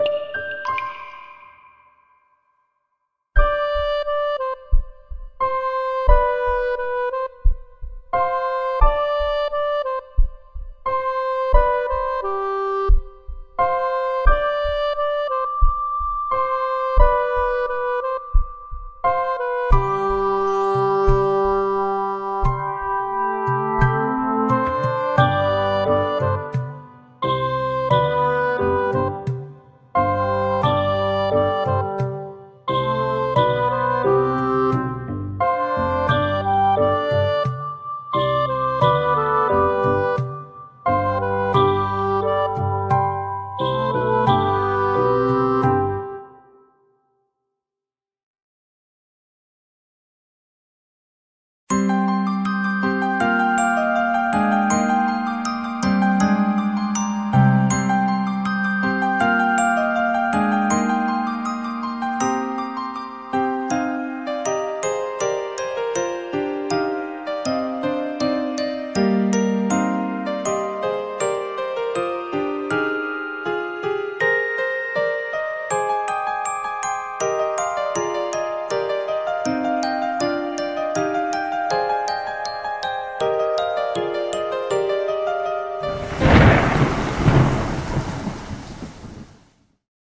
CM風声劇「ロゼの大木で逢いましょう」